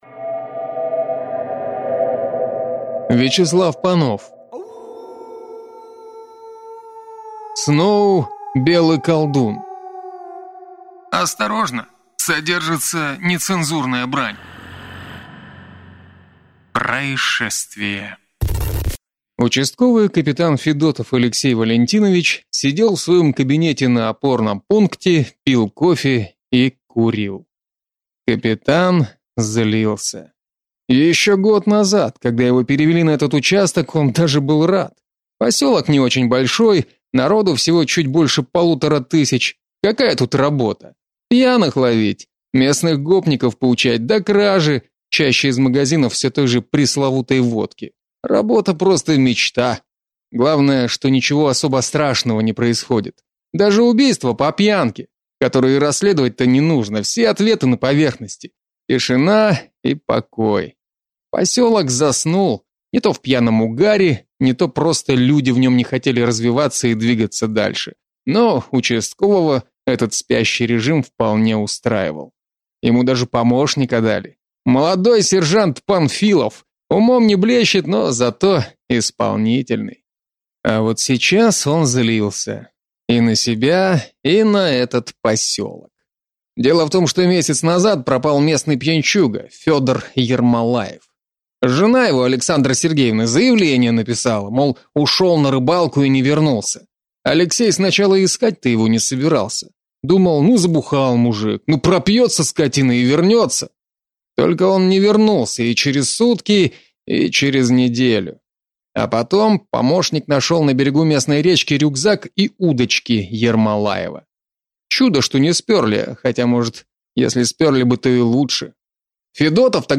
Аудиокнига Сноу белый колдун | Библиотека аудиокниг